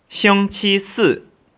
(Click on any Chinese character to hear it pronounced.